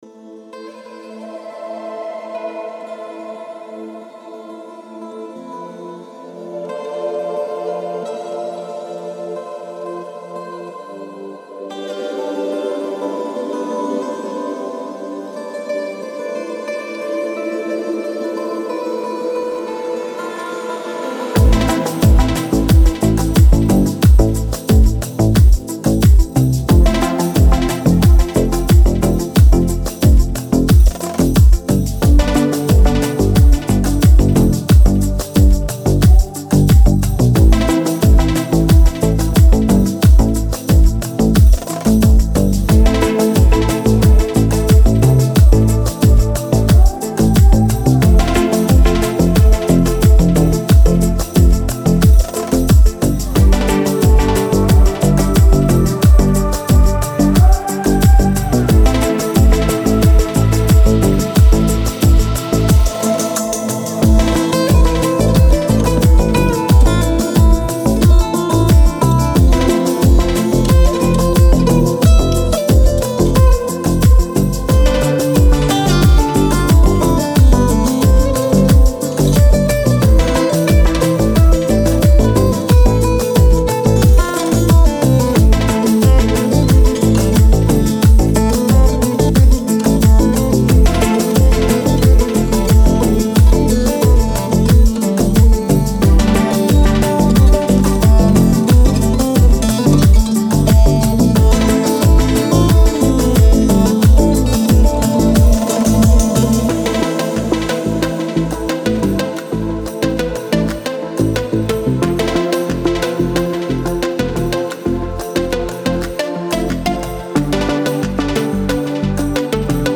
دیپ هاوس